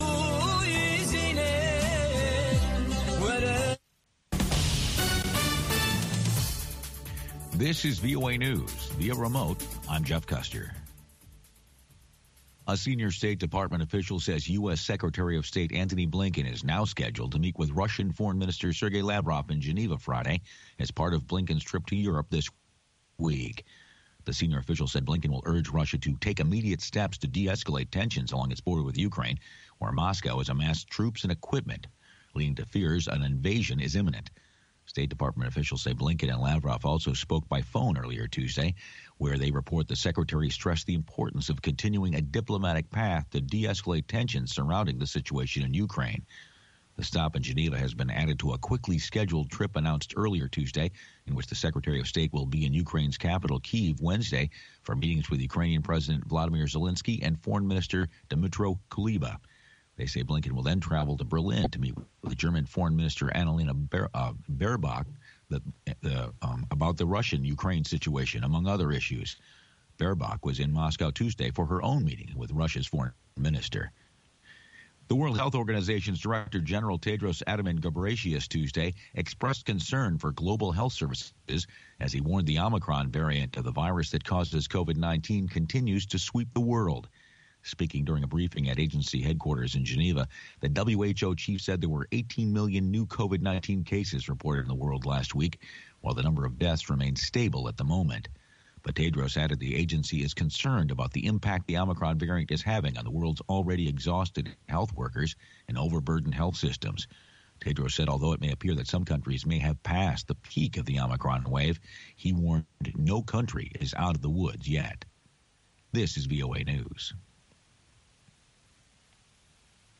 هەواڵە جیهانیـیەکان لە دەنگی ئەمەریکا